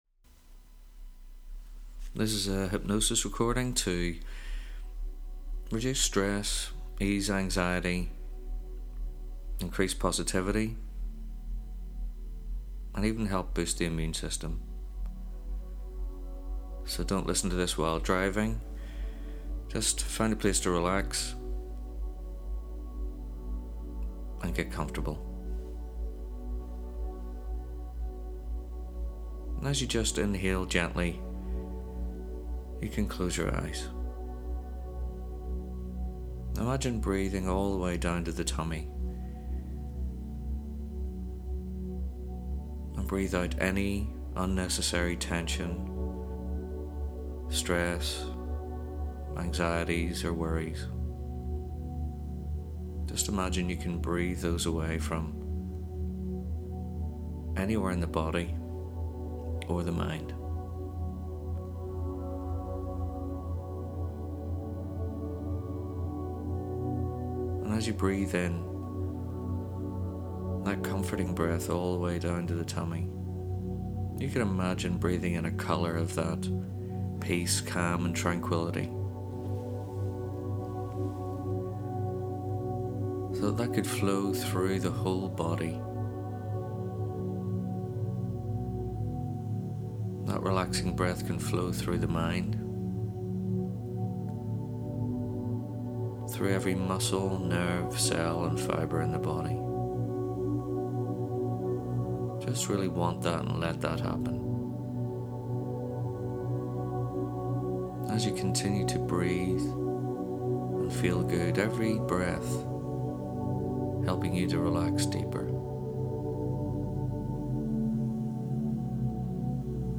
Relaxing, calming & positive energy Hypnosis recording
I’ve just made a new free hypnotherapy recording for you. It will help you to relax, ease anxiety, increase positivity and boost your body’s natural immune system.